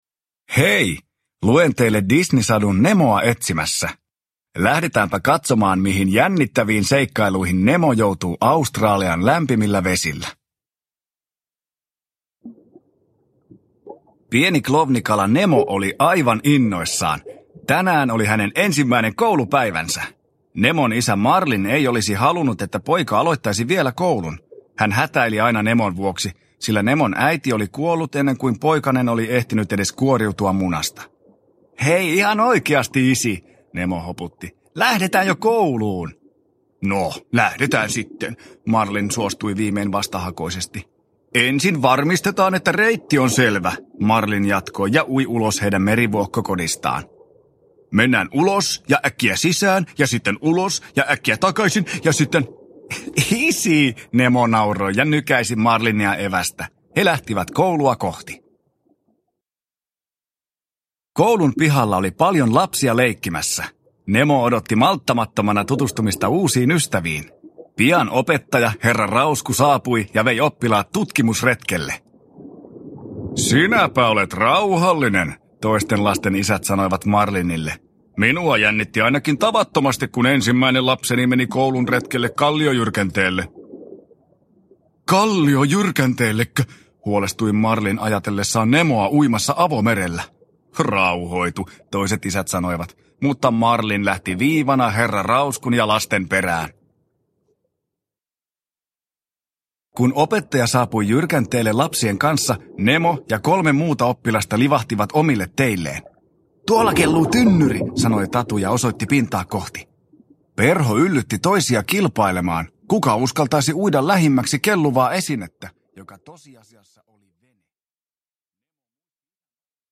Nemoa etsimässä – Ljudbok – Laddas ner
Uppläsare: Petteri Summanen